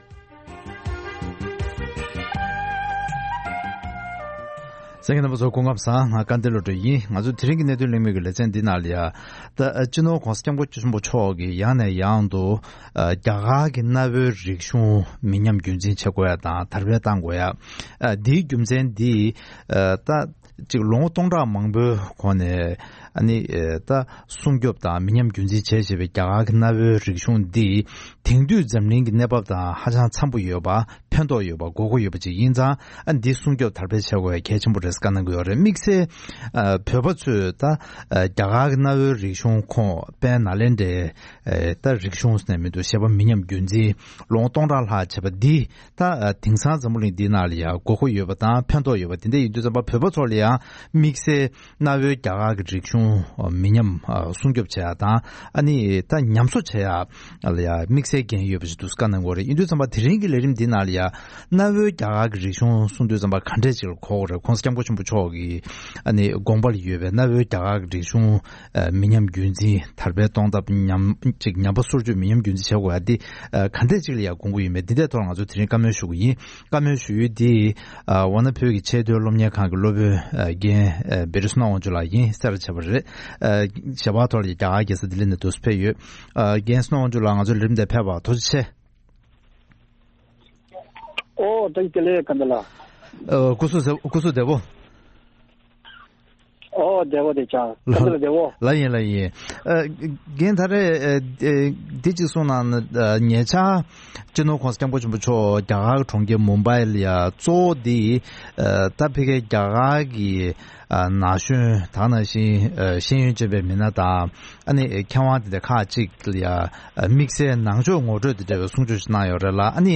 གནའ་བོའི་རྒྱ་གར་གྱི་རིག་གཞུང་གི་གོ་དོན་དང་འབྲེལ་བའི་ཐད་གླེང་མོལ།